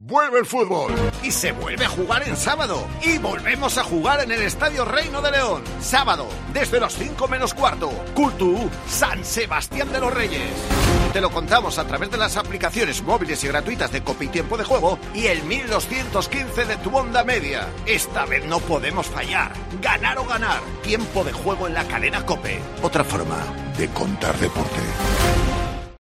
Escucha la cuña promocional del partido Cultural-San Sebastián Reyes día 20-11-21 a las 17:00 h en el 1.215 OM